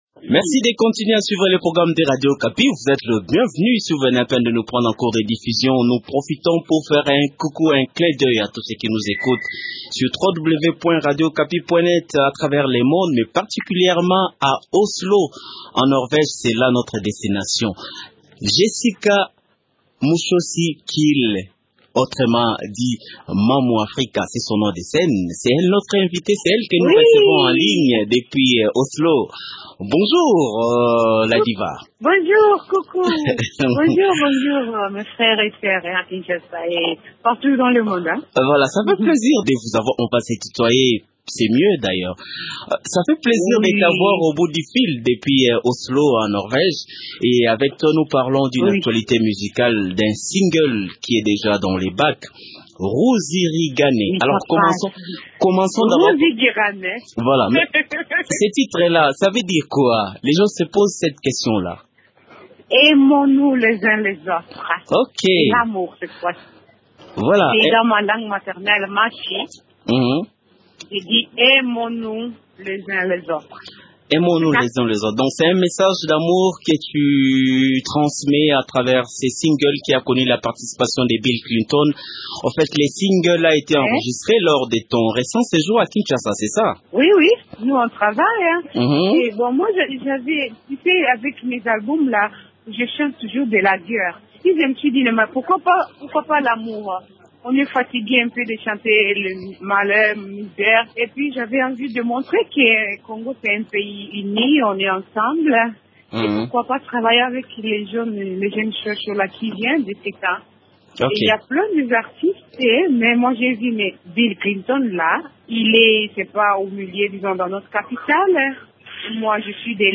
s’entretient au téléphone avec